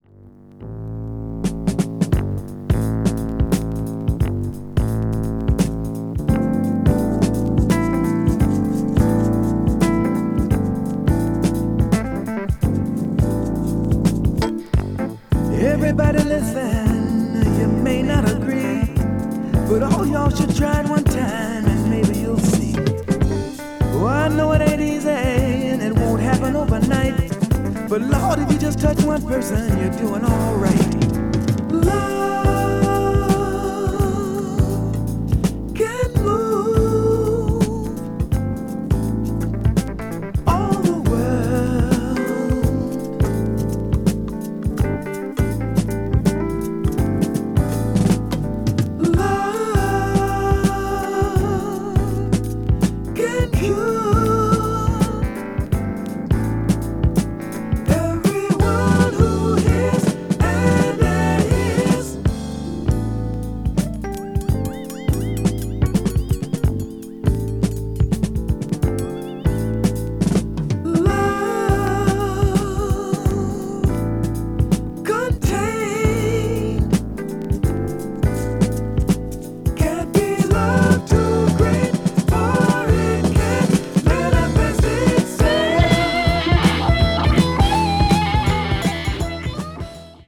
crossover   fusion   jazz funk   jazz groove   mellow groove